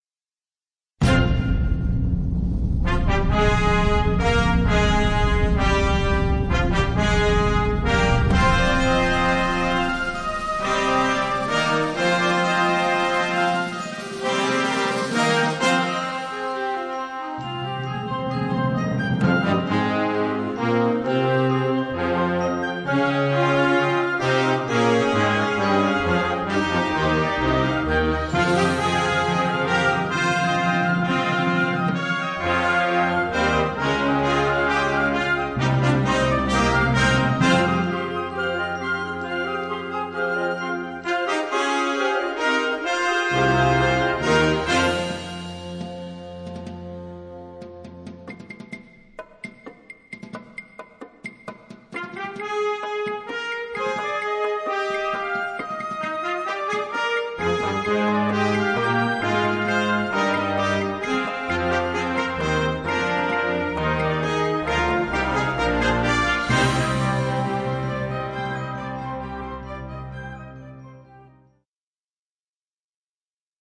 Catégorie Harmonie/Fanfare/Brass-band
Sous-catégorie Ouvertures (œuvres originales)
Instrumentation Ha (orchestre d'harmonie)